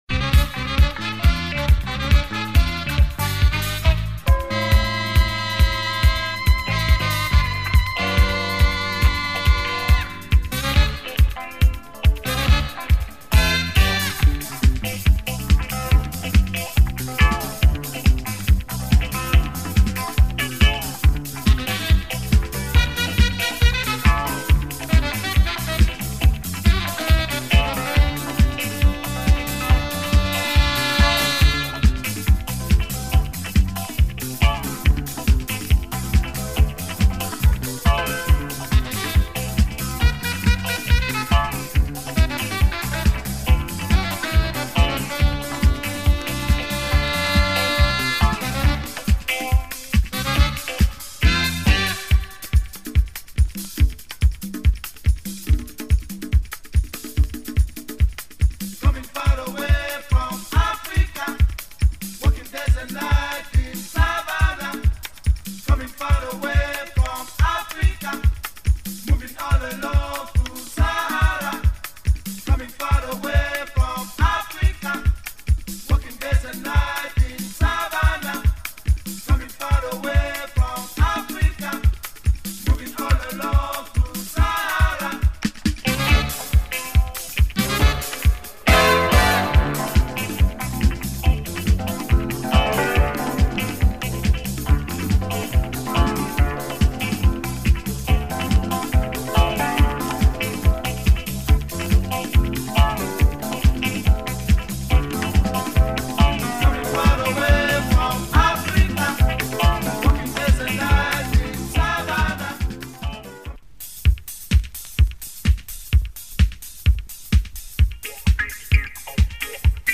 Cosmic afro disco